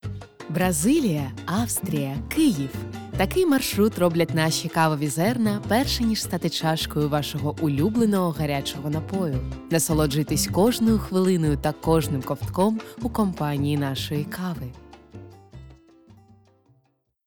Diep, Natuurlijk, Veelzijdig
Commercieel